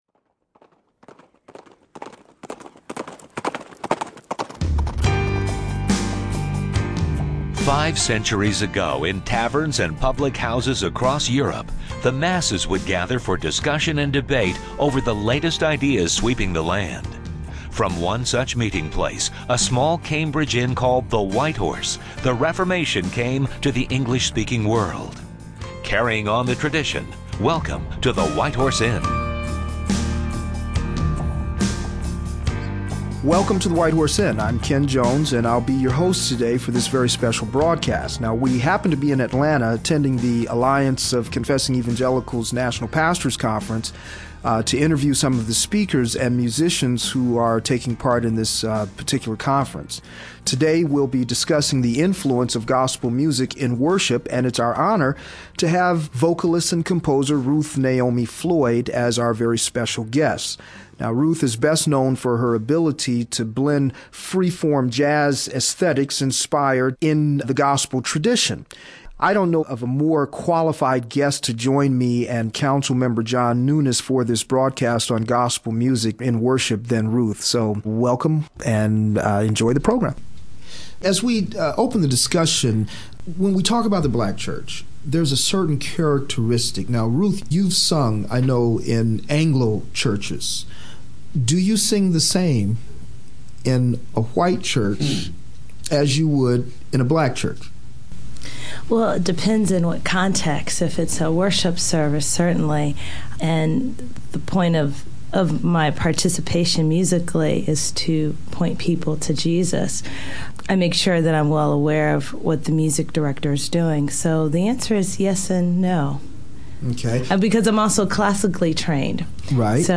Event(s): The Alliance of Confessing Evangelicals National Pastors' Conference